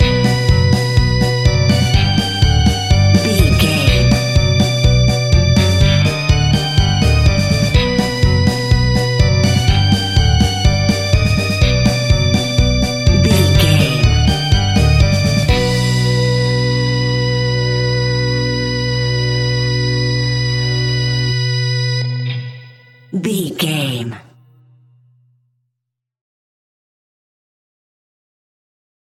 Diminished
scary
ominous
dark
eerie
electric organ
piano
bass guitar
drums
spooky
horror music